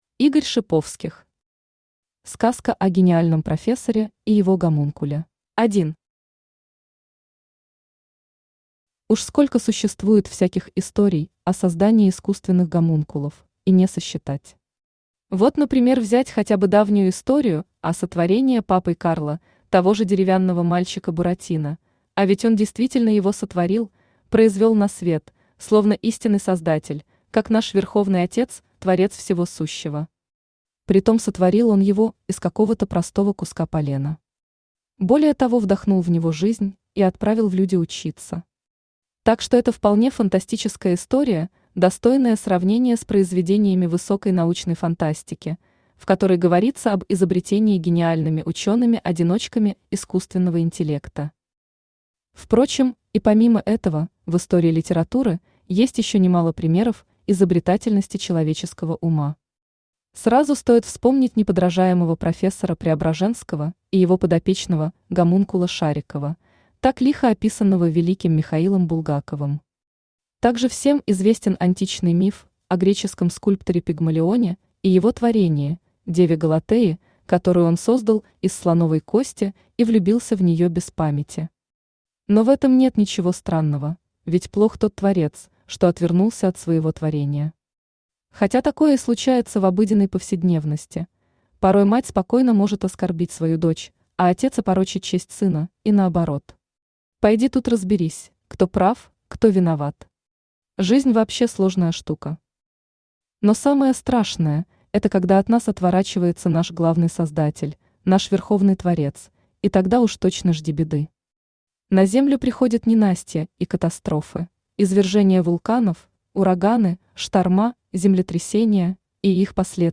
Аудиокнига Сказка о гениальном профессоре и его гомункуле | Библиотека аудиокниг
Aудиокнига Сказка о гениальном профессоре и его гомункуле Автор Игорь Дасиевич Шиповских Читает аудиокнигу Авточтец ЛитРес.